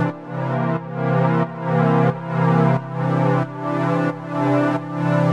GnS_Pad-alesis1:4_90-C.wav